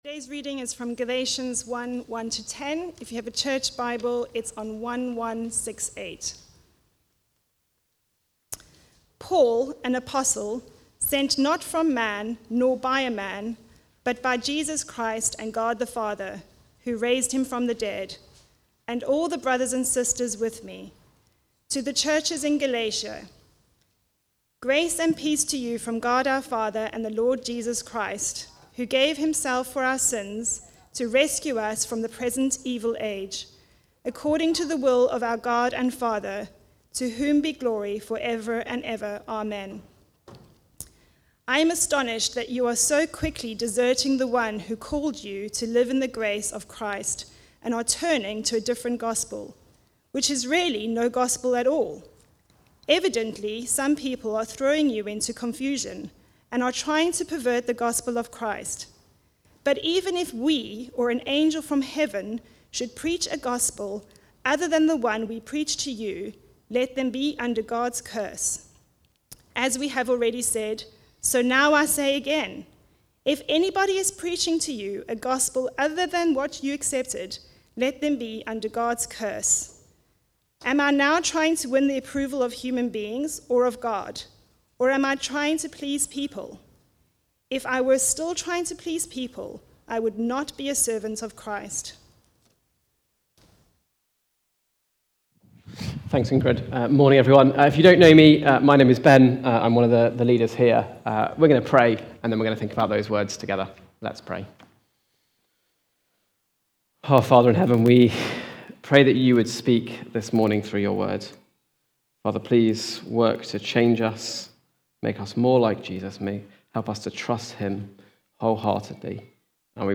Preaching
No Other Gospel (Galatians 1:1-10) from the series Galatians - the Glorious Gospel. Recorded at Woodstock Road Baptist Church on 08 September 2024.